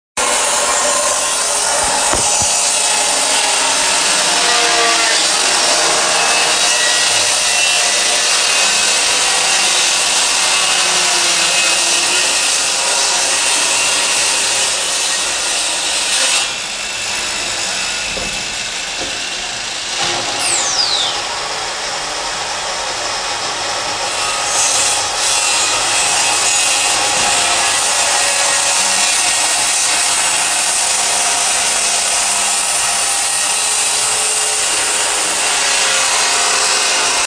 Jingle Jangle Keys and Locks
Location- Outside my dorm room in the main hall, Hague house in the Netherlands North side.
Sounds in Clip- The jungle of keys, the scrape of metal on metal, soft voices in the background of people in the hall, the unlocking of the door, and the click lock of the dead bolt.